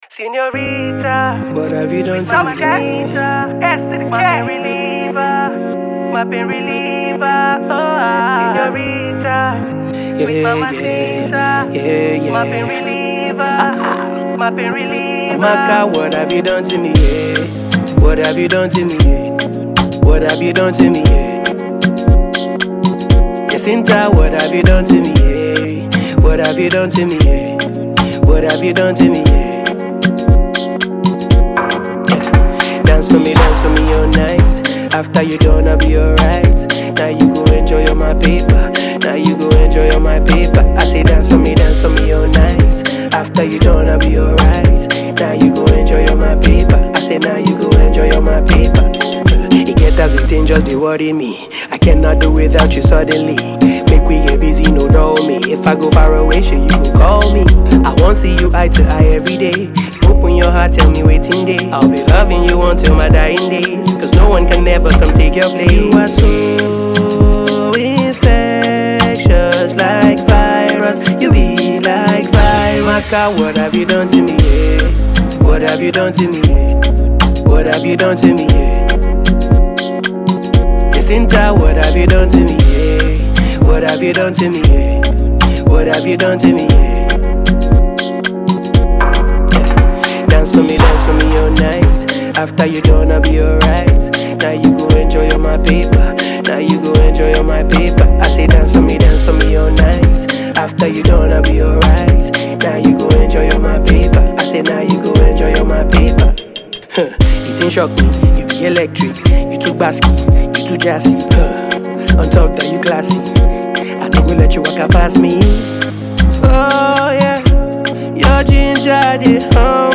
Energetic Nigerian singer and songwriter
afrobeat